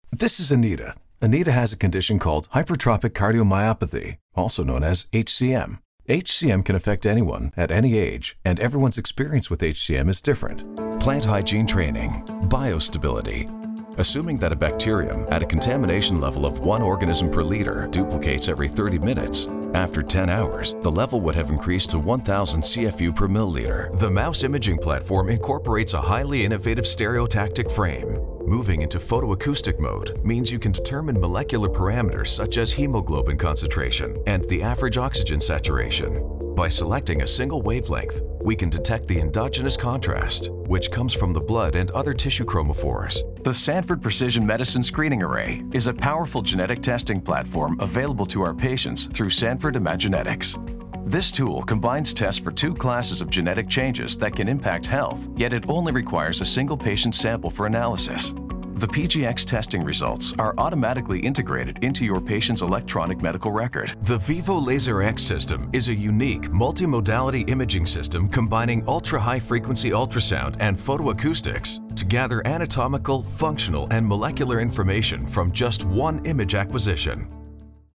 Male
Authoritative, Confident, Conversational, Engaging, Friendly, Natural
Neutral, North American, Standard American Accent, Canadian
corporate.mp3
Microphone: Rode NT2-A
Audio equipment: Rode NT2-A mic, dbx 286A preamp, Audient iD14 interface, Pro Tools 12, Mac OS X, Presonus E5 XT studio monitors